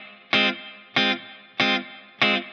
DD_TeleChop_95-Fmin.wav